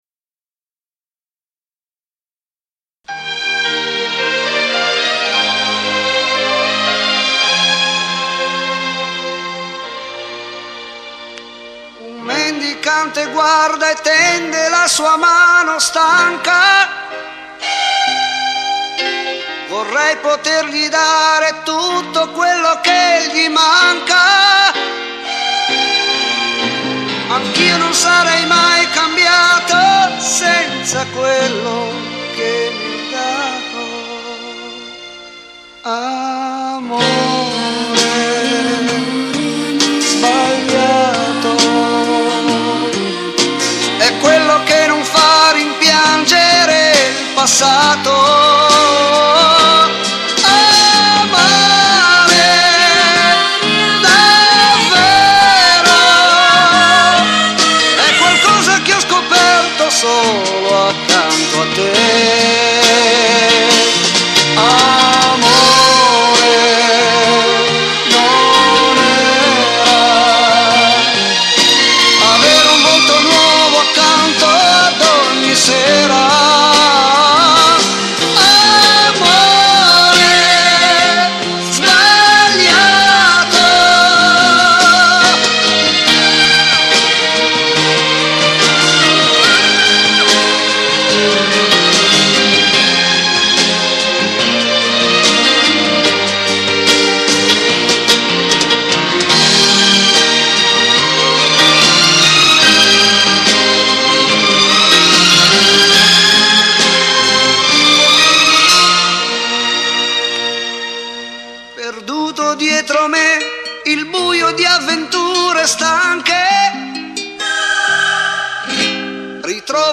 CHITARRA ACUSTICA, ELETTRICA
PERCUSSIONI
BASSO
PIANO, MOOG